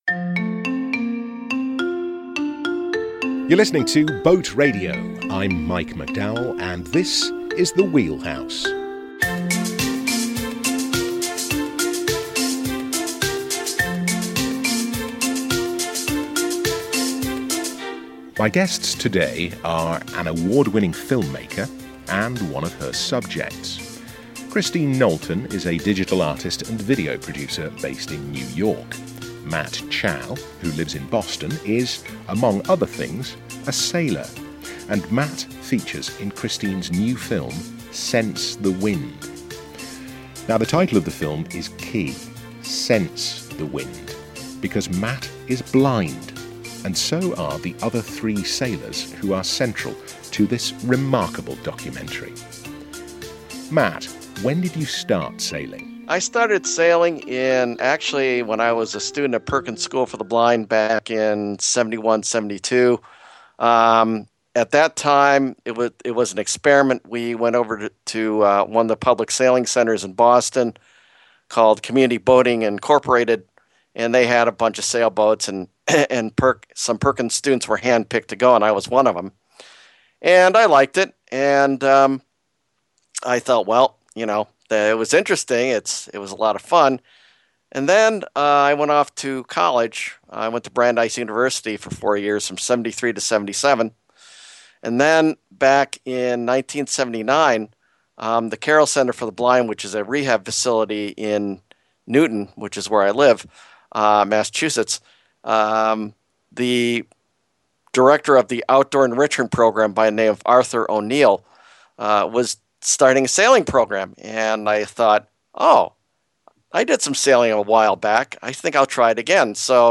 Plus, of course, learn how a blind sailor really does 'sense the wind'. This interview is absolutely bloody marvellous.